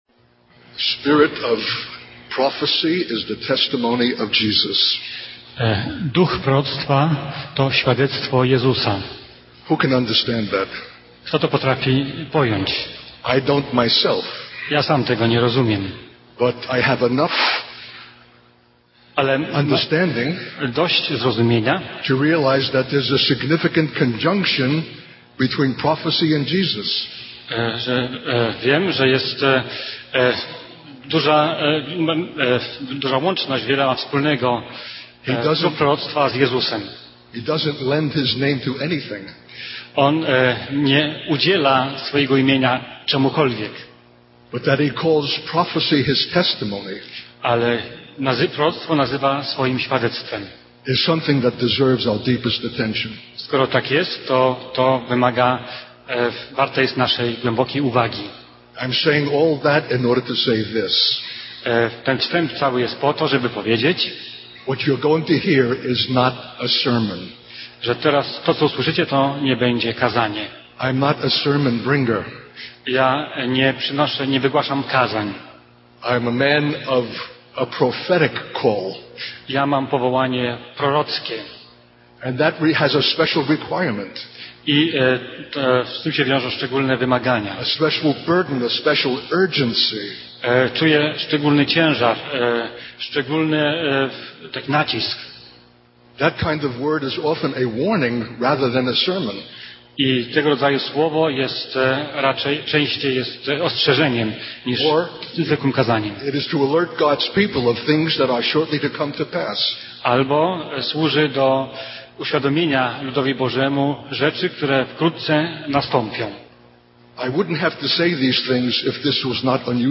In this sermon, the speaker emphasizes the importance of recognizing that we are living in the last days and approaching the end of the age. He highlights the significance of the Lord's coming, the restoration of His people, and the establishment of His kingdom. The speaker warns that even though we may claim to know the Lord, we often fail to truly understand the gravity of the times we are living in.